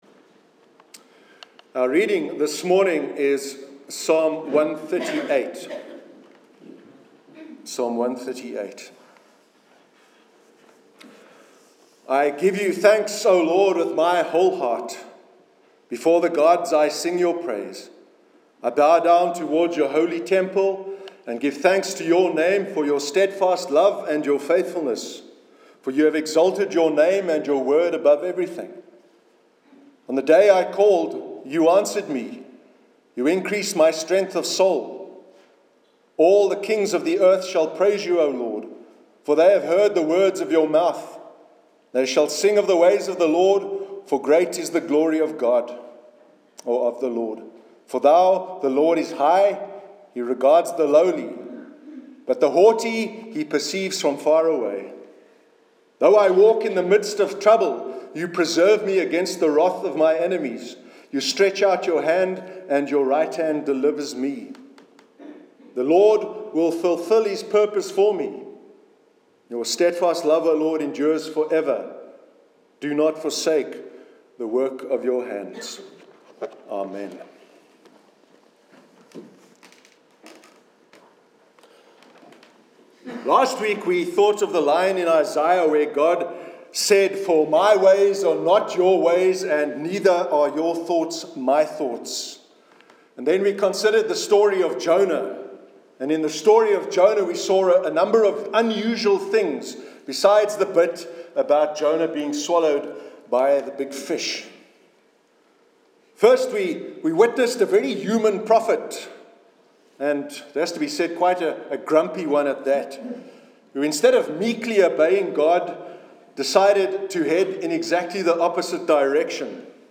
Sermon 15th January 2017